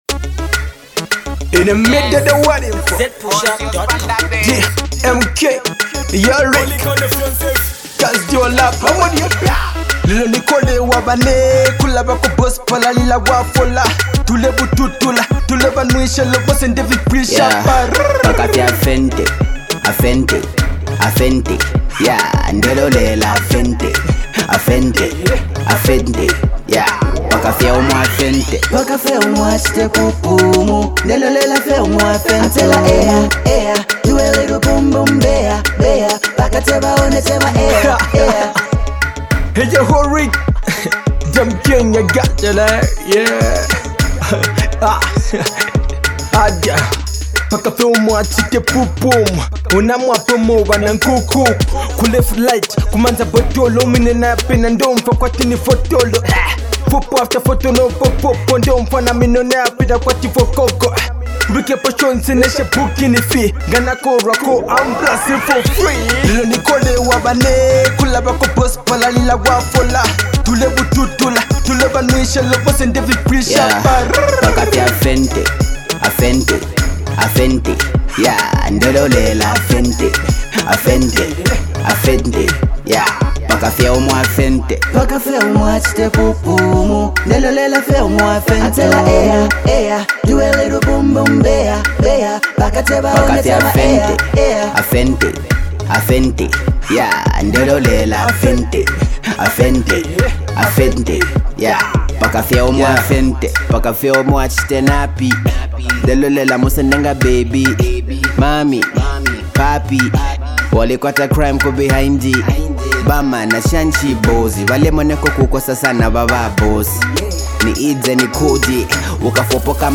dance-hall festival track